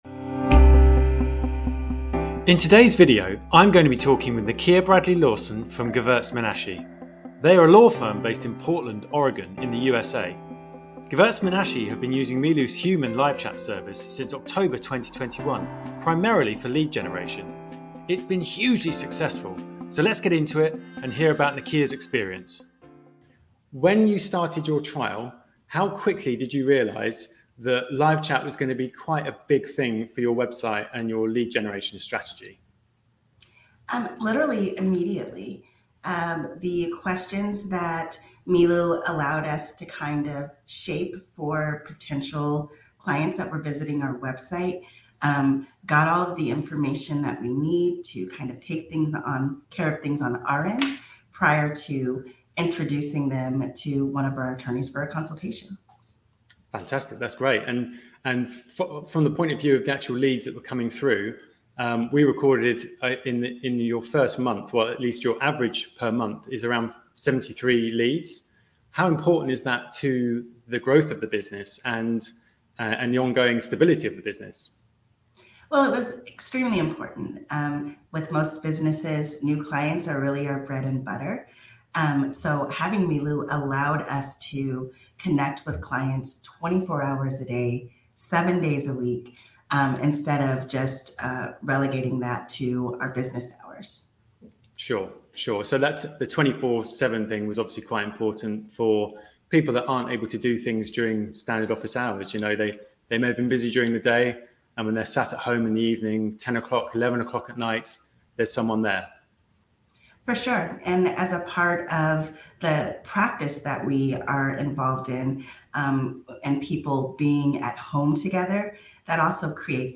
Client Case Study with Gevurtz Menashe